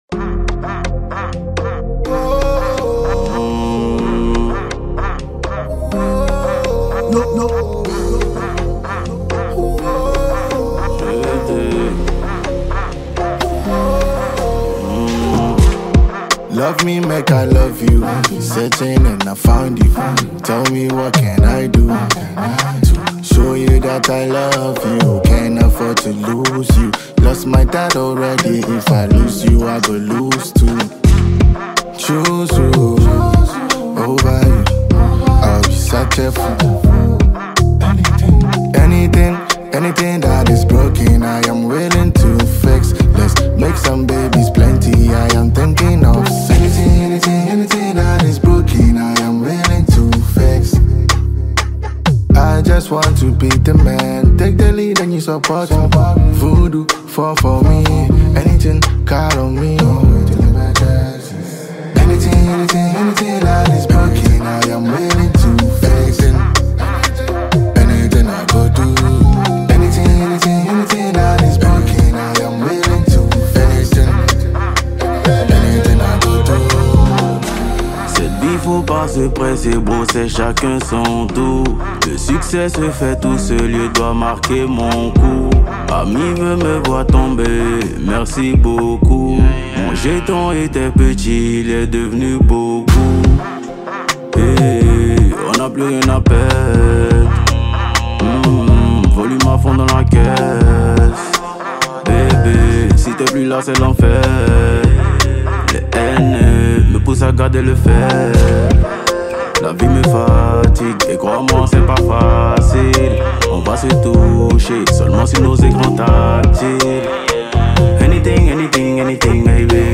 Genre: Rap / Hip-Hop